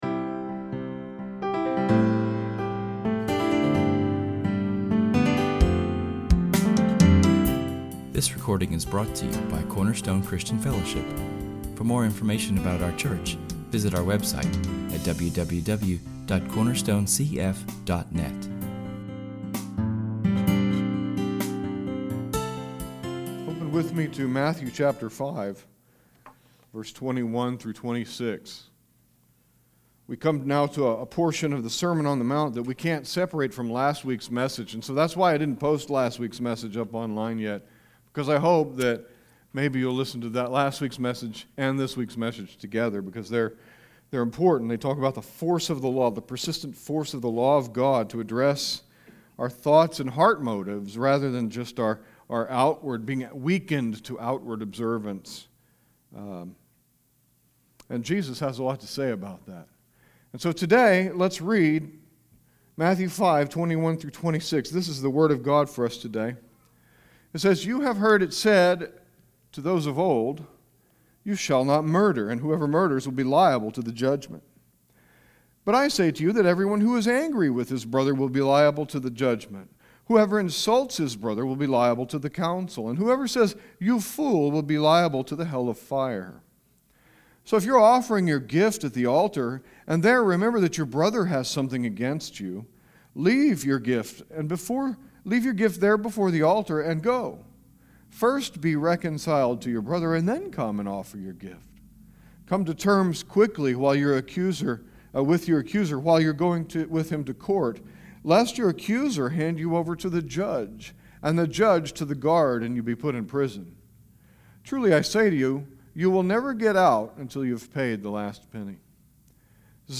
Public reading: [esvignore]Psalm 119:1-24[/esvignore]; Benediction: [esvignore]Romans 15:13[/esvignore] Matthew 5:21-26 Psalm 119:1-24 Romans 15:13